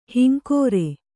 ♪ hinkōre